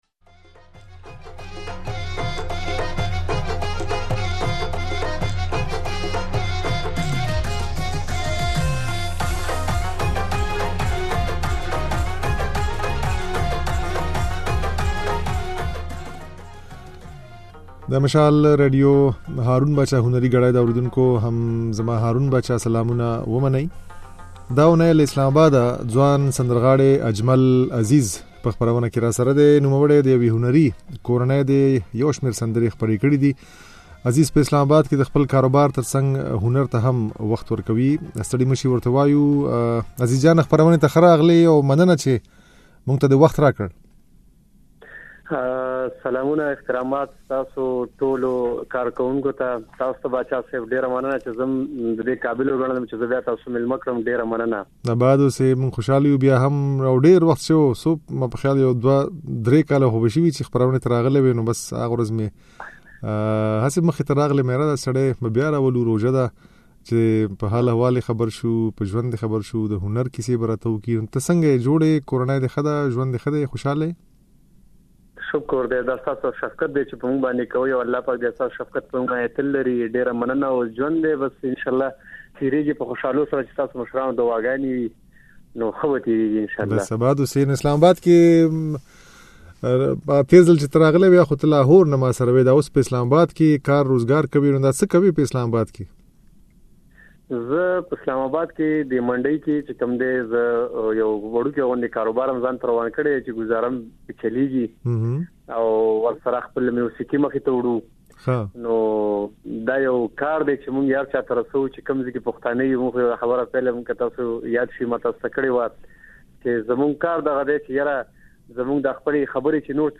ځينې سندرې يې په خپرونه کې اورېدای شئ.